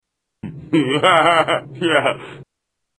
Laugh 1